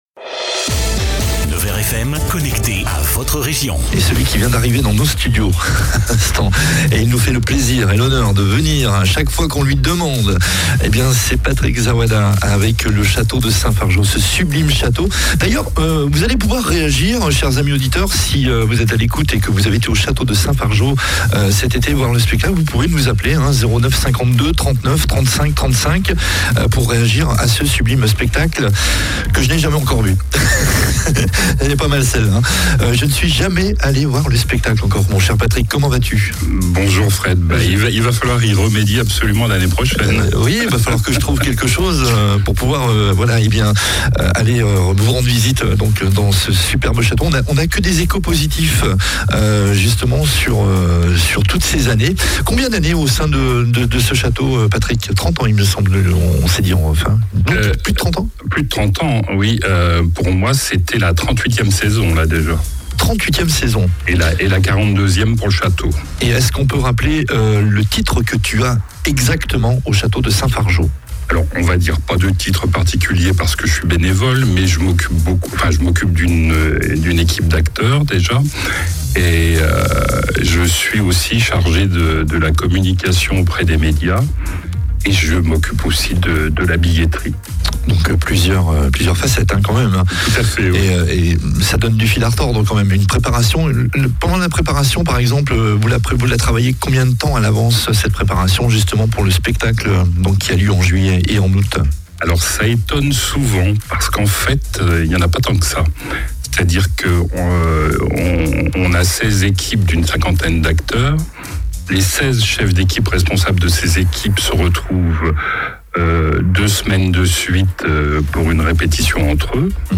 Il est même venu aux studios pendant le 8-12 afin de nous en dire plus ! Restez jusqu'à la fin, un très bel évènement est annoncé pour la commune et le château !